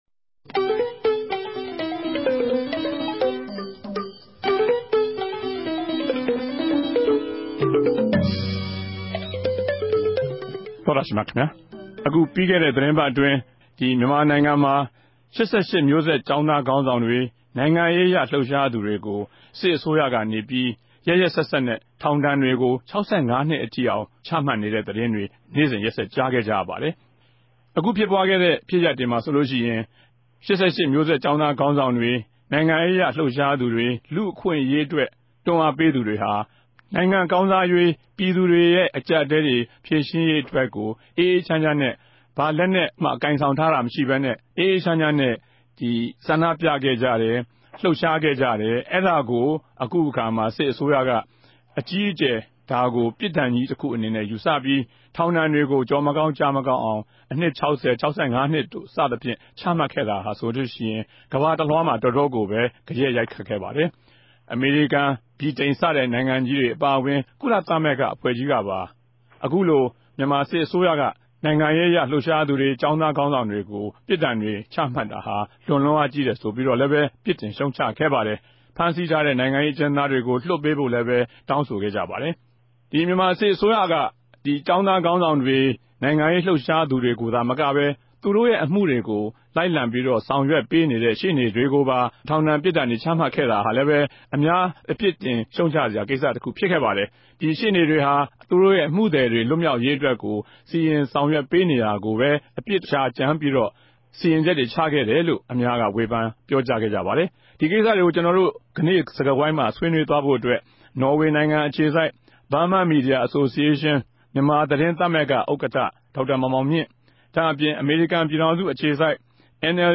ိံိုင်ငံရေးနဲႚလူႚအခြင့်အရေး လြပ်ရြားသူတေကြို ူပင်းထန်တဲ့ူပစ်ဒဏ်တြေ ခဵမြတ်နေတဲ့အပေၞ ဆြေးေိံြးခဵက်